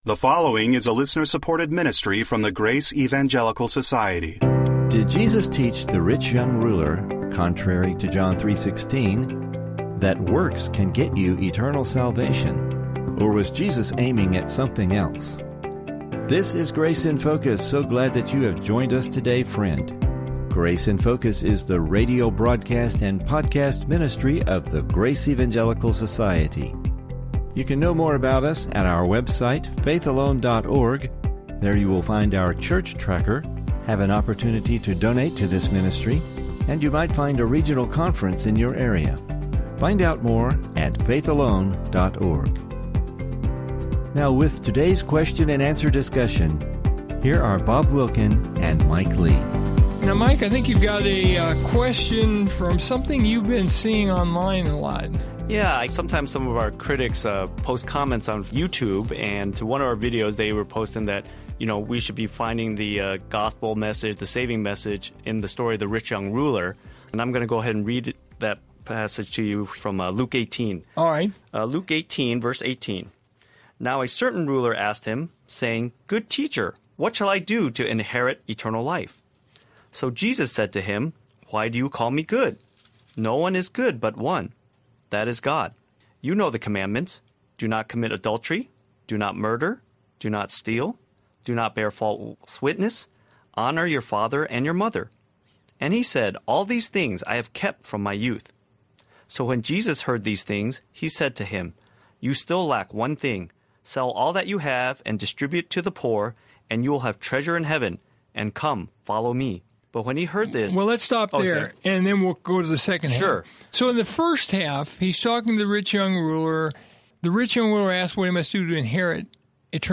Is Jesus teaching here, contrary to John 3:16, that works can get you eternal salvation? Or did His words to the rich, young ruler seek to shake him up and give him something to think about? Please listen for some interesting Biblical discussion regarding this passage!